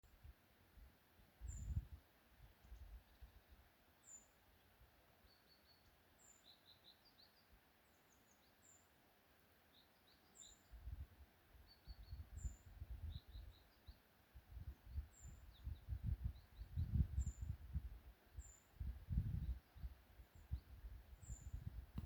Common Treecreeper, Certhia familiaris
Administratīvā teritorijaEngures novads
StatusVoice, calls heard